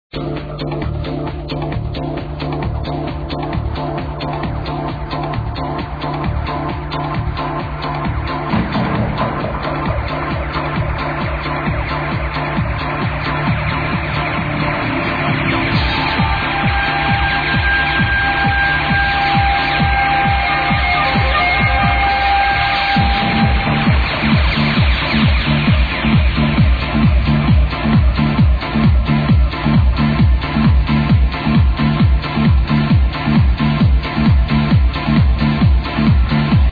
prog trancer